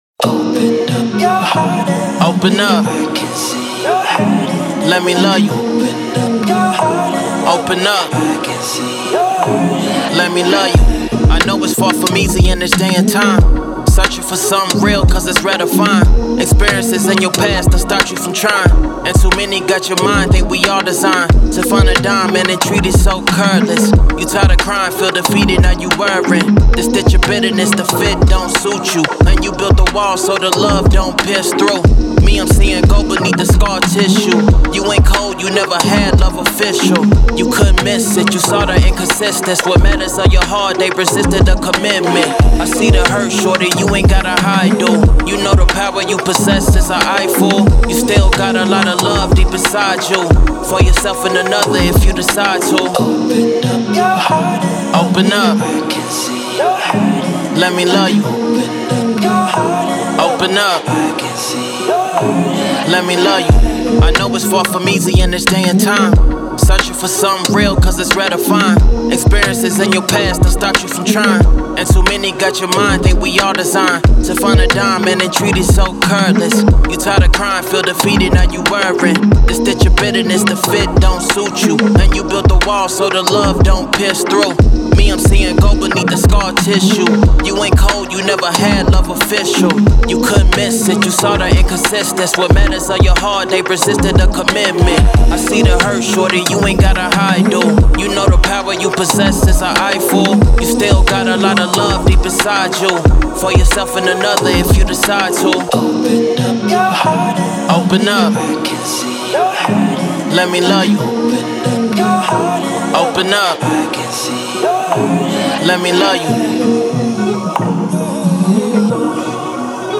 Hip Hop, R&B
A#m
Hip-Hop/R&B, self empowerment after breakup.